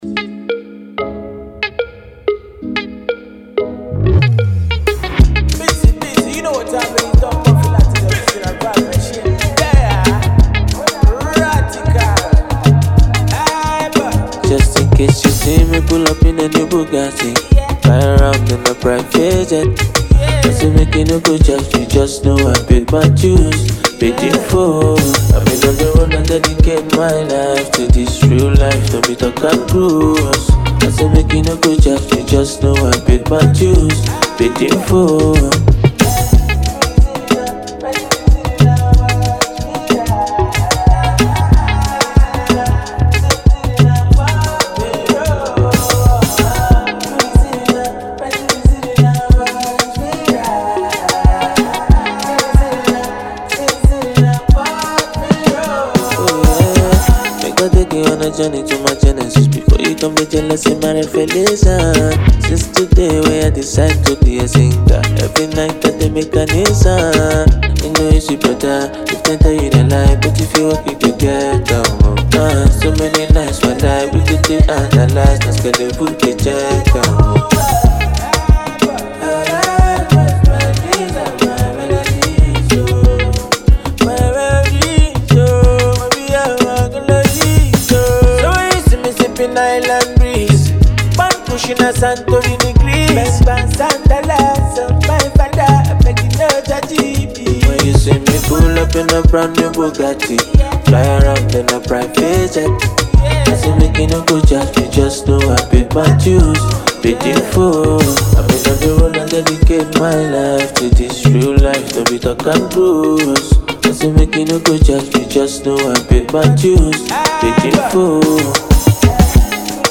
a Nigerian musician